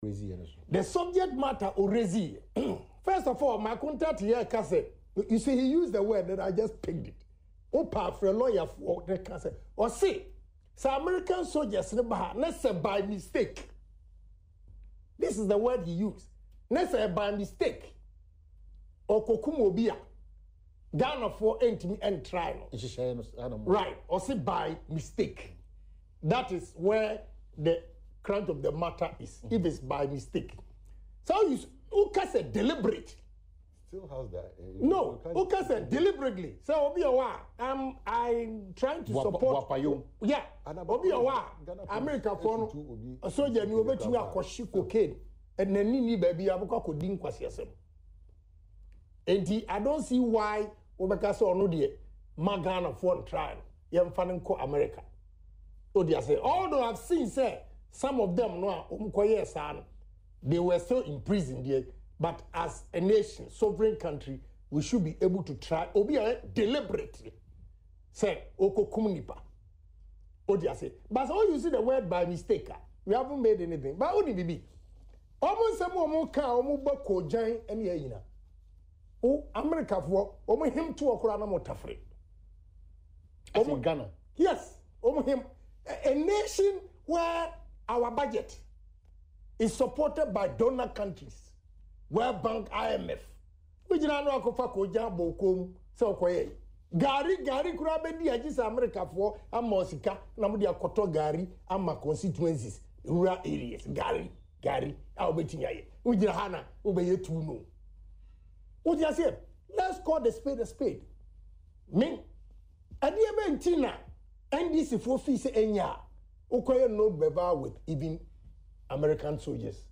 Kennedy Agyapong who was speaking on Adom TV’s Morning Show “Badwam” Tuesday  said he was surprised  at the stance  of the NDC Members of Parliament whose tenure saw a similar agreement signed in 1998 and 2015.